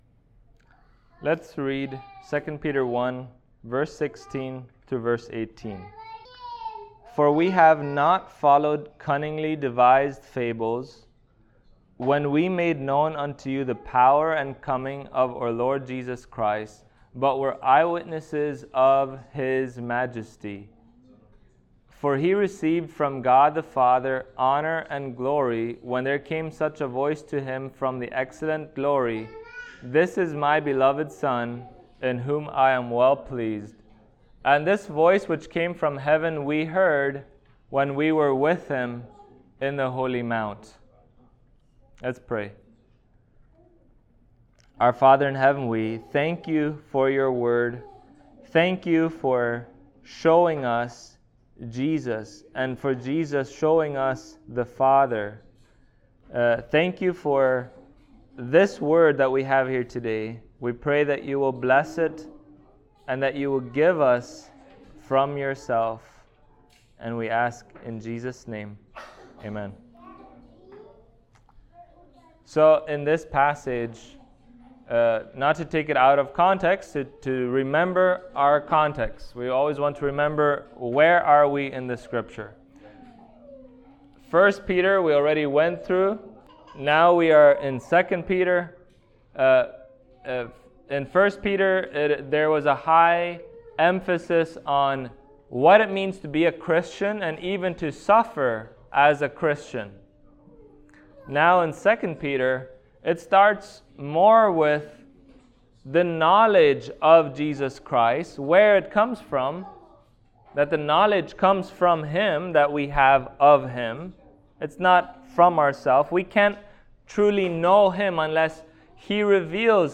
2 Peter 1:16-18 Service Type: Sunday Morning Topics: Glory of Christ « Faithful Reminders of Truth.